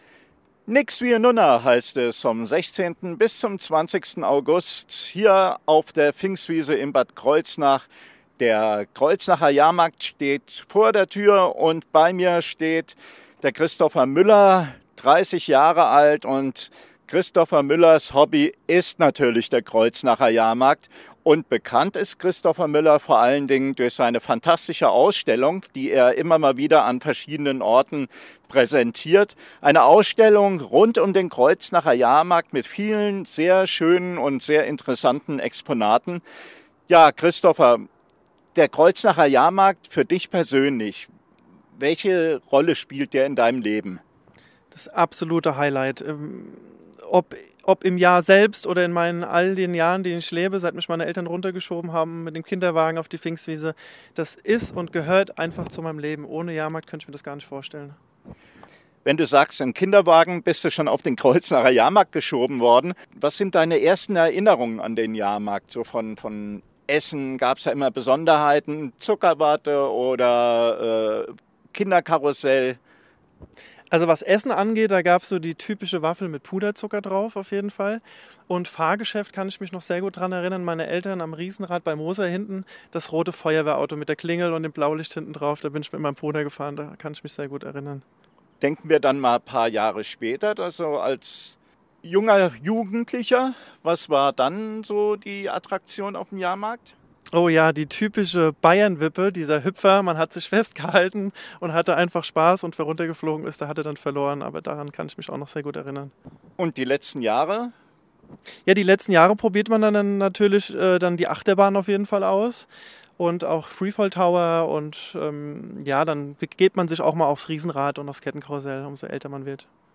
14.08.19 – JAHRMARKT 2019